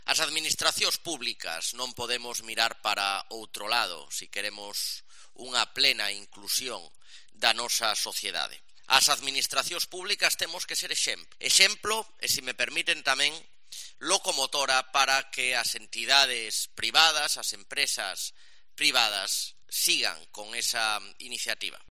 AUDIO: El conselleiro, José Manuel Rey Varela, habla del papel que desarrollan las administraciones en la inclusión laboral del colectivo.